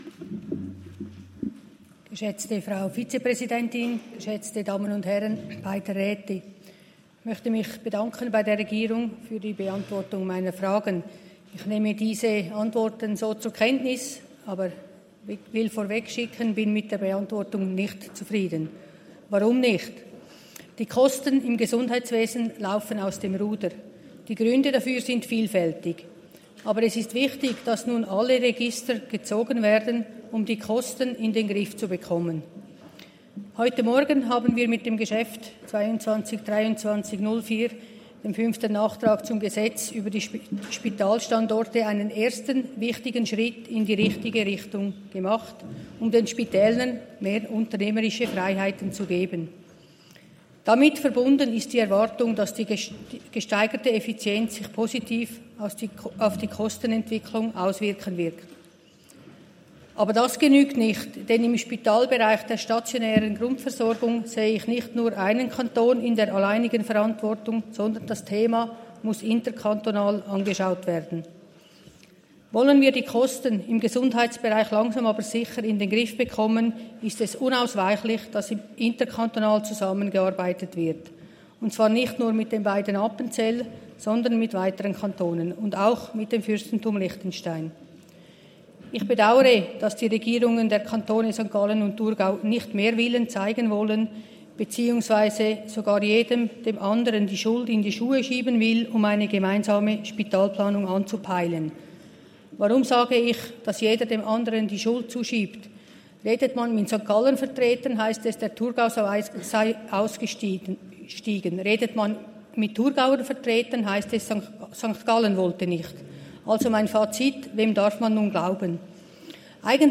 Session des Kantonsrates vom 29. April bis 2. Mai 2024, Aufräumsession